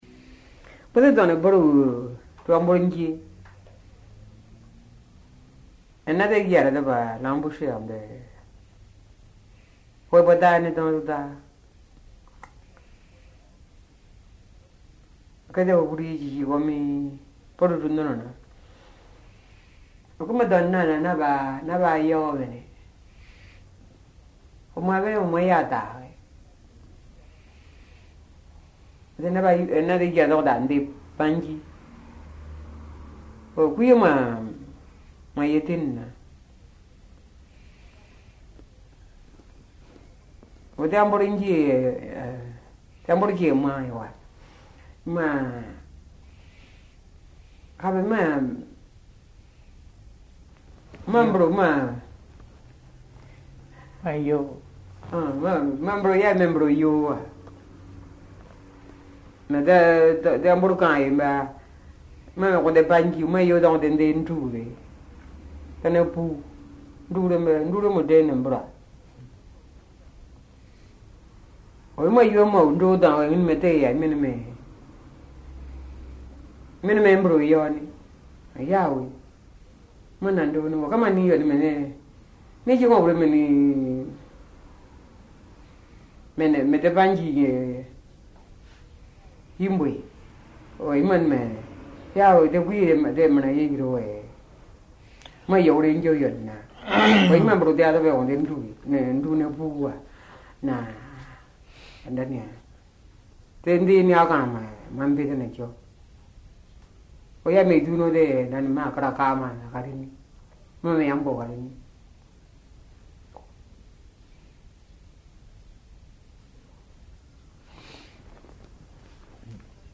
Documents joints Dialogue leçon 24 ( MP3 - 3.4 Mio ) Un message, un commentaire ?